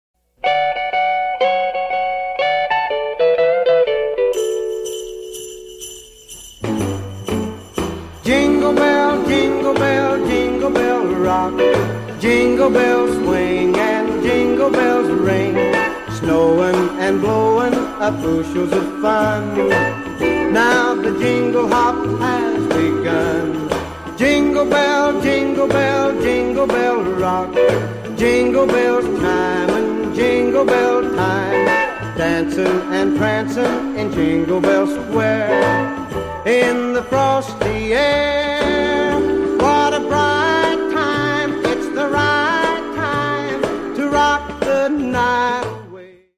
Bells.mp3